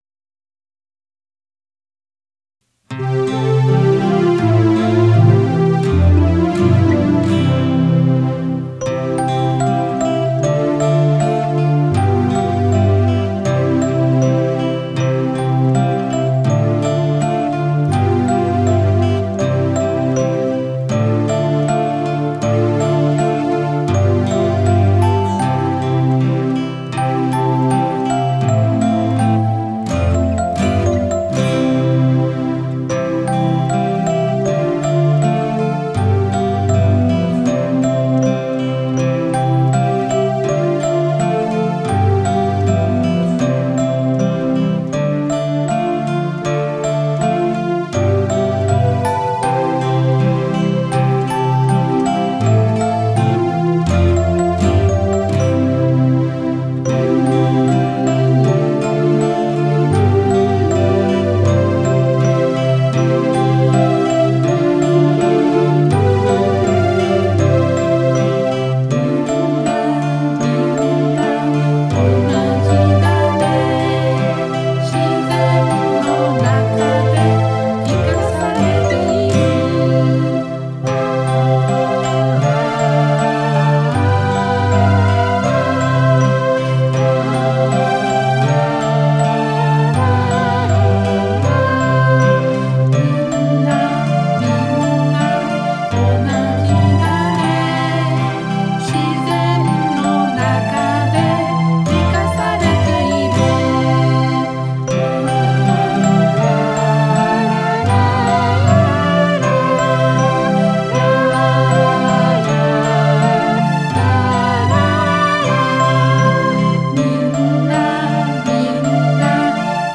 カラオケ音源 (ガイドメロディ入り) をダウンロード